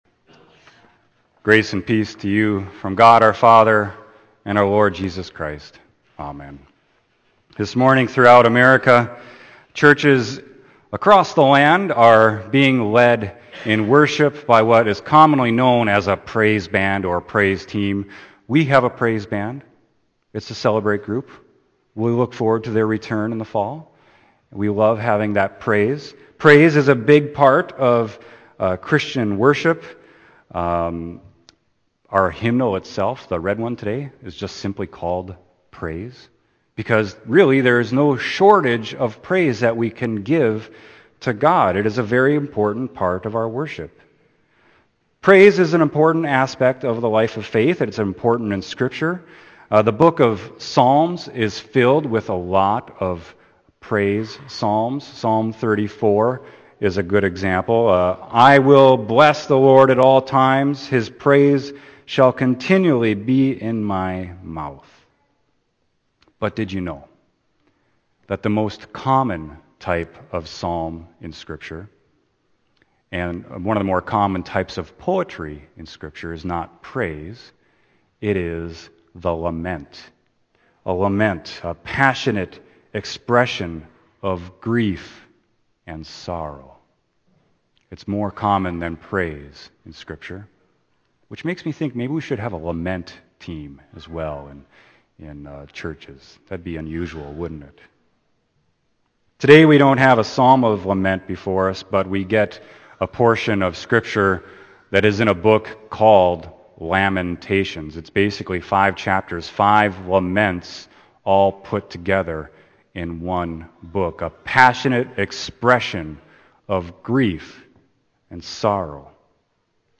Sermon: Lamentations 3.22-33